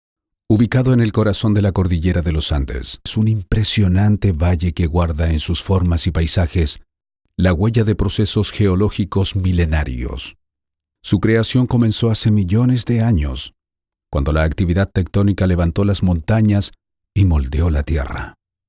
Commerciale, Profonde, Naturelle, Polyvalente, Corporative, Jeune, Senior, Urbaine, Cool, Mature, Amicale
Corporate